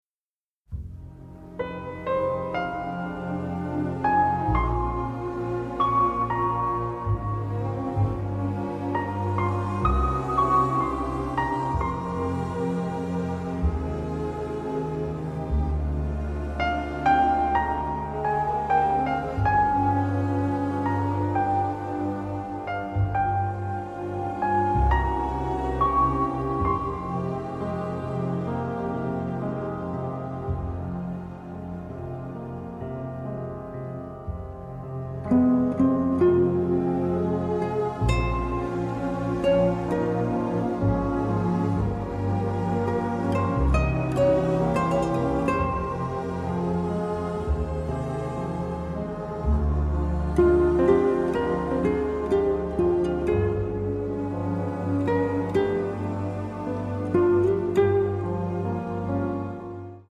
Additional Music (mono)